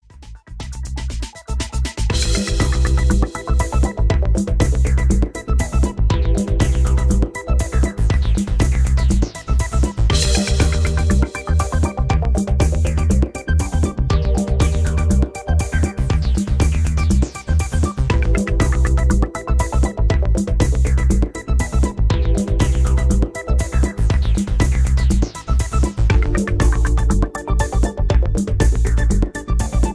Electro Ambient with feel of tension